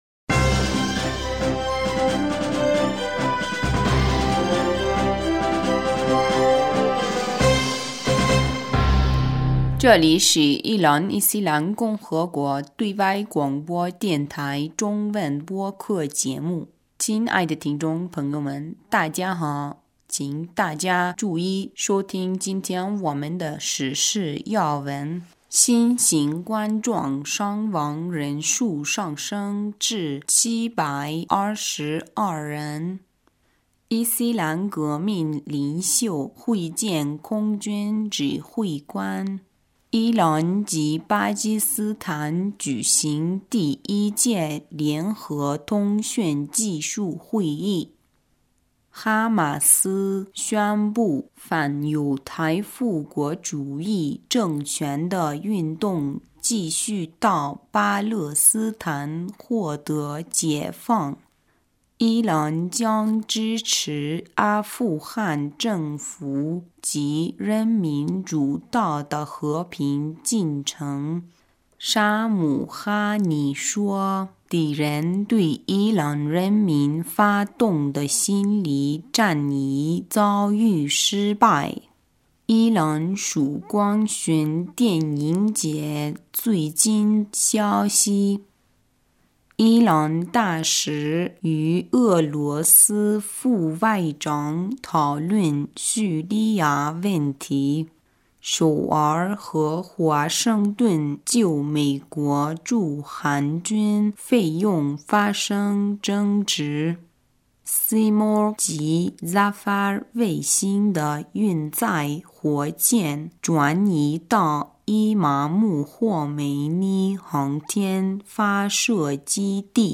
2020 年2月8日 新闻
伊朗华语台消息：2020 年2月8日 新闻